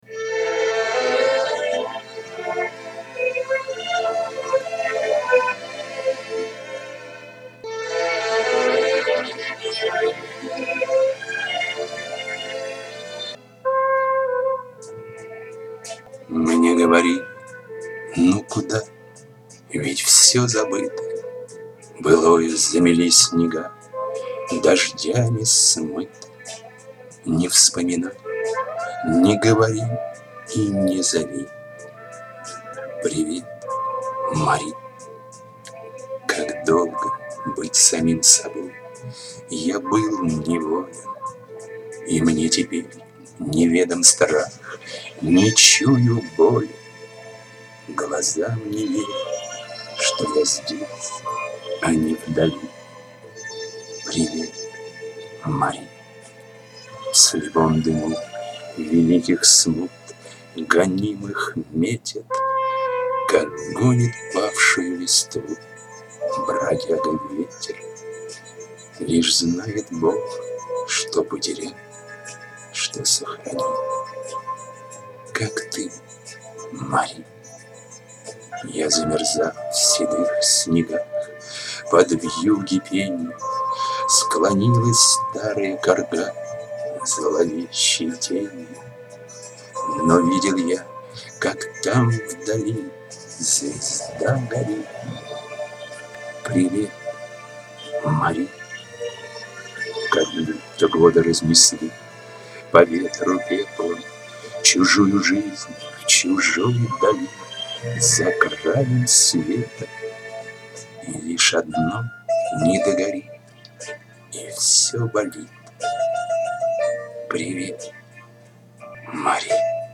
Сижу,слушаю и улыбаюсь.Нравится мне ваша декламация, несмотря на "плавающее"муз.сопровождение))
которой я пишу. очень несовершенна, глотает буквы, а то и слоги, тянет звук и даже меняет скорость. надеюсь найти что-то получше.